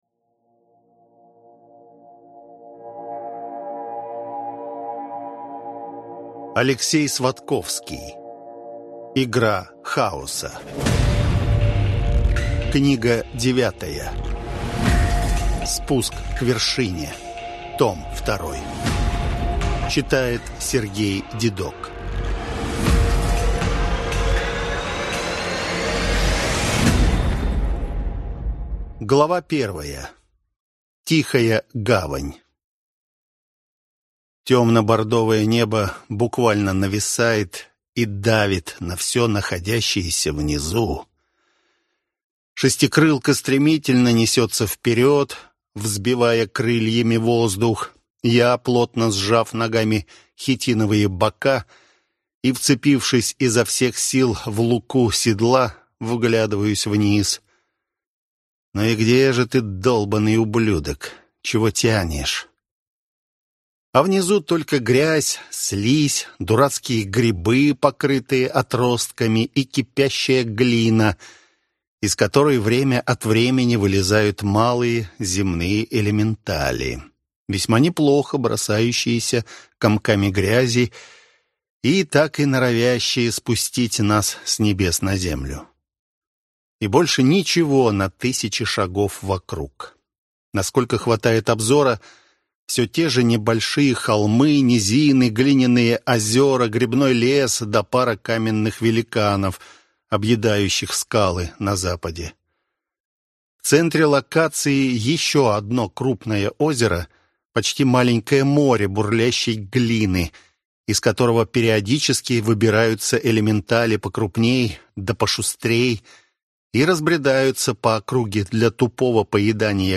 Аудиокнига. Война домов, тайны и интриги в Совете Старших, все это меркнет, когда приходит время турнира